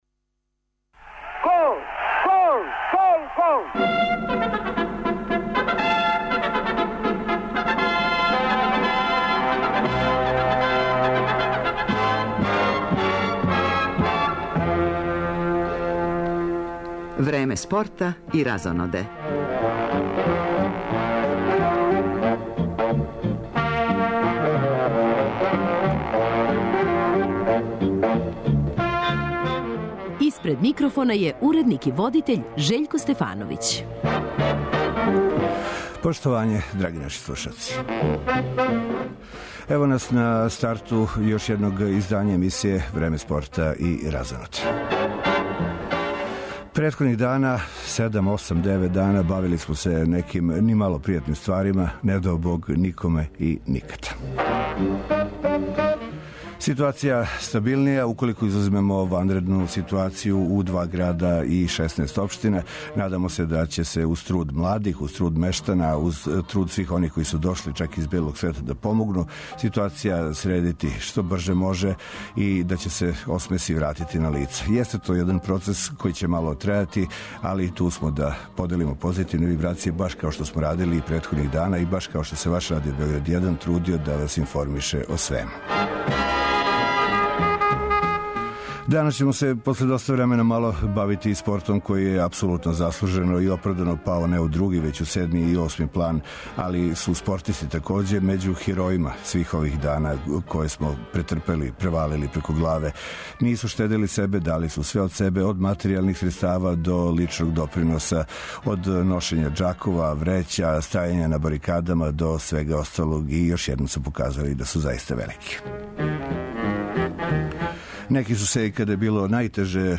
Као и претходних дана, Радио Београд 1 ће и у овом термину пратити информације везане за незапамћену катастрофу која је погодила нашу земљу, уз укључења репортера из крајева погођених поплавама.
У гостима су нам јуниори кошаркашког клуба Црвена звезда који су се окитили титулом првака Европе у својој генерацији.